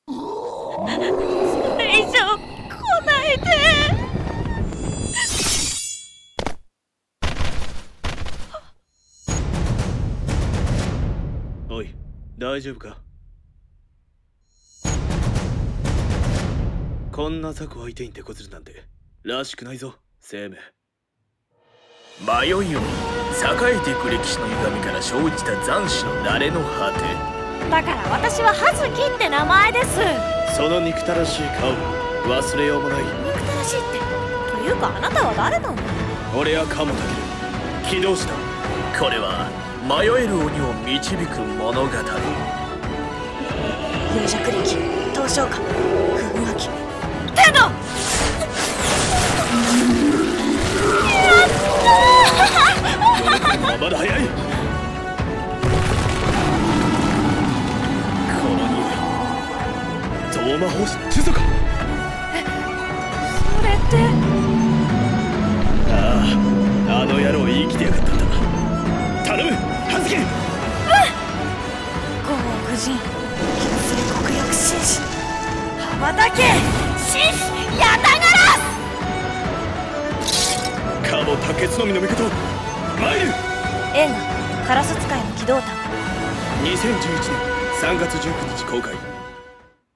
【映画予告風】鴉使いの鬼導譚【2人用声劇】